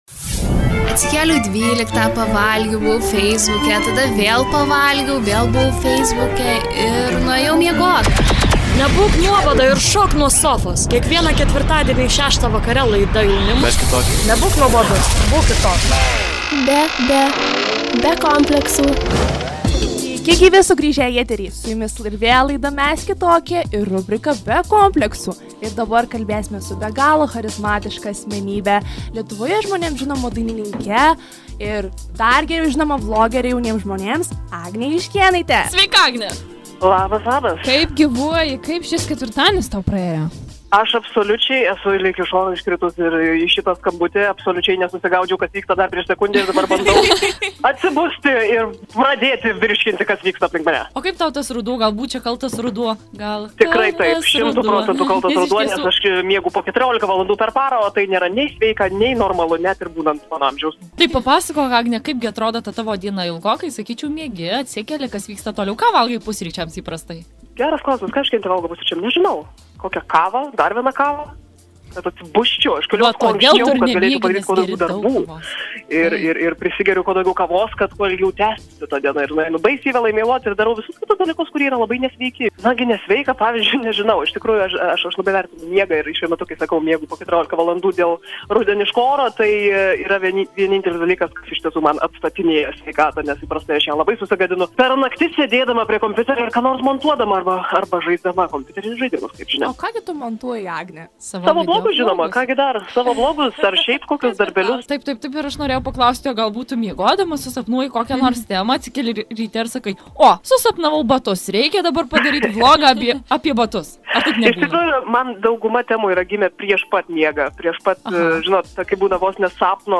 Jaunatviškas draivas, kitoks požiūris ir charizmatiški pašnekovai – visa tai ir dar daugiau laidoje jaunimui „Mes Kitokie“!